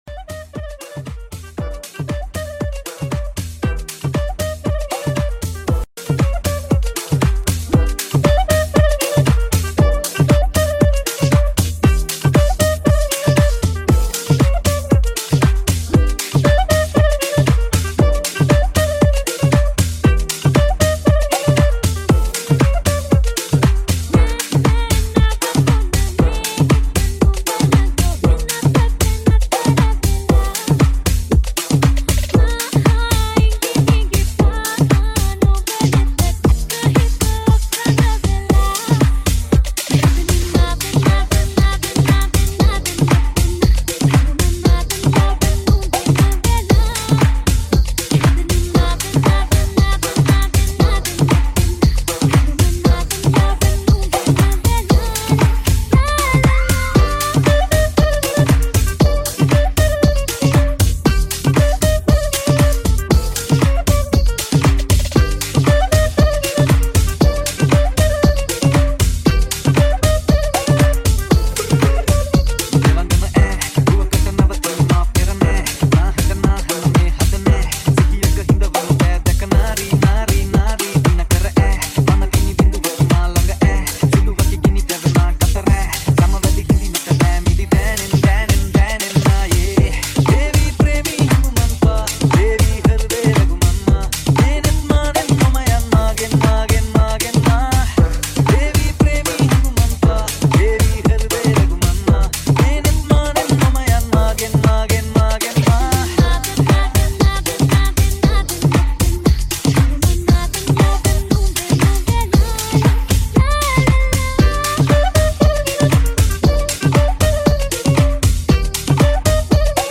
Nonstop Remix